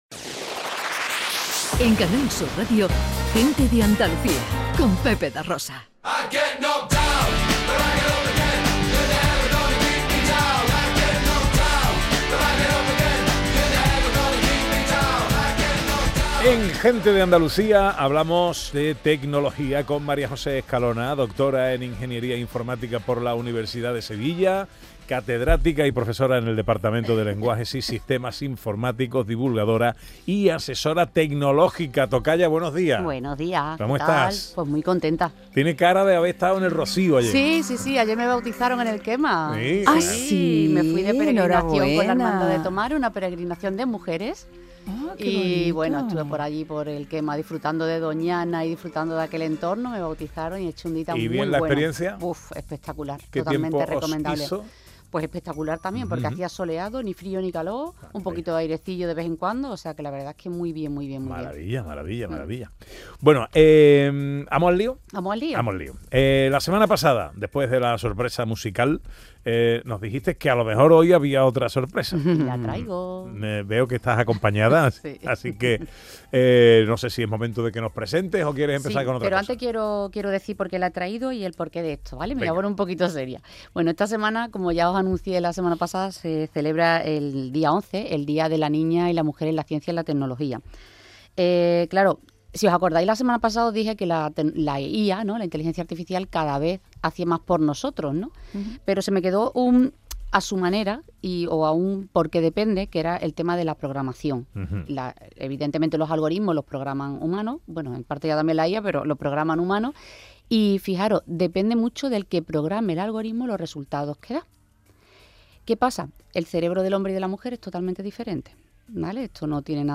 Segunda hora programa especial fin de temporada desde el Hotel Barceló Punta Umbría Mar.